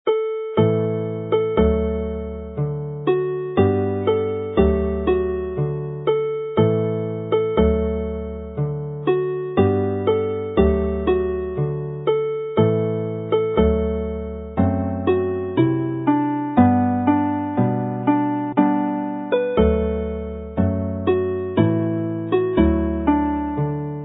Alawon Cymreig - Set Ar Lan y Môr set - Welsh folk tunes
On the sea shore (3/4 time)